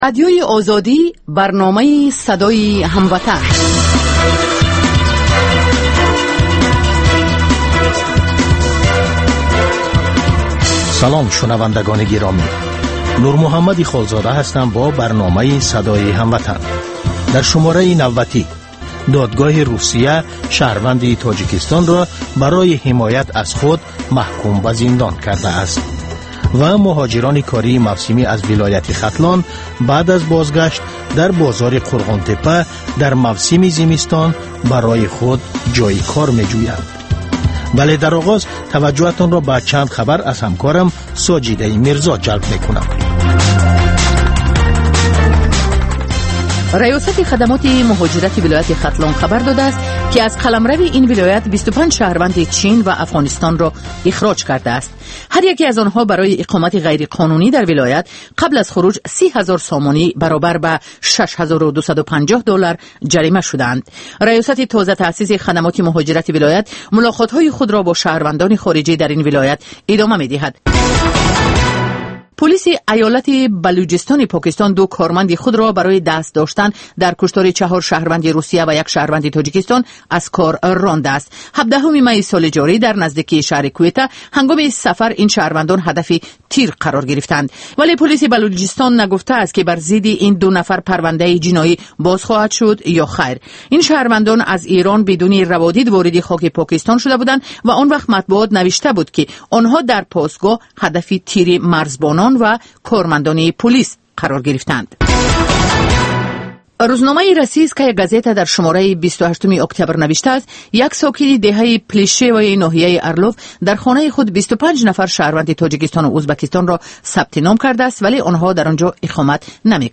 Тоҷикон дар кишварҳои дигар чӣ гуна зиндагӣ мекунанд, намунаҳои комёб ва нобарори муҳоҷирон дар мамолики дигар, мусоҳиба бо одамони наҷиб.